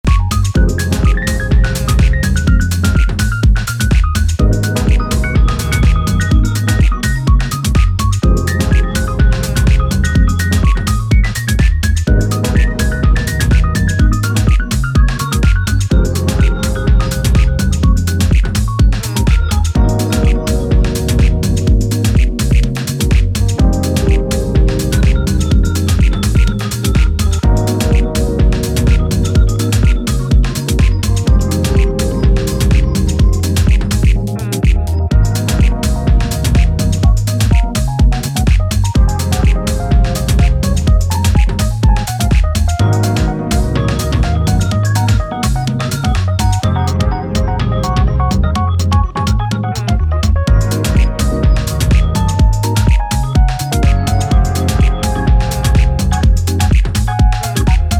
ここでは、ジャジーで豊潤なメロディーが情感を駆り立てる、ウォームでグルーヴィーなディープ・ハウス群を展開。